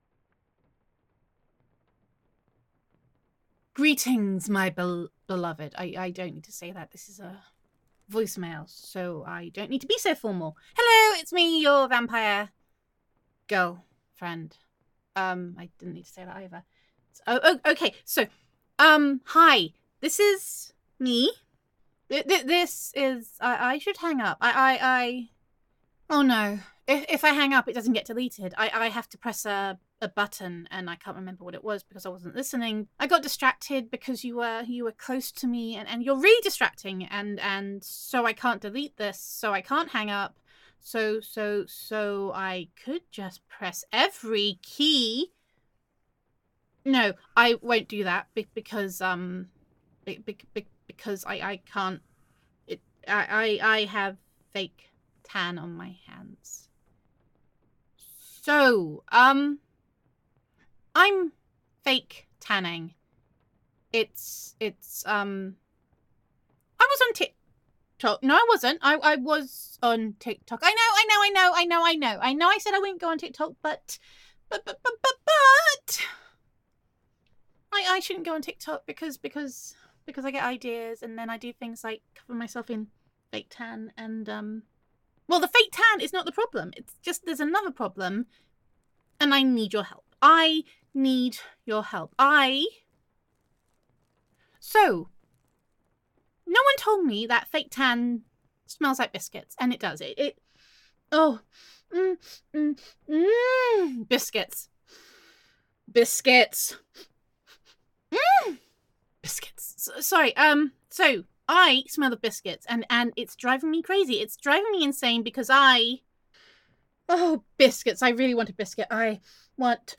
[F4A] Biscuit Addiction Transcends Death [Girlfriend Roleplay][Vampire Roleplay][Monster Girl][Girlfriend Voicemail][Voicemail][TikTok][Fake Tan][Gender Neutral][Whenever Your Vampire Girlfriend Calls You It Is Never a Good Thing]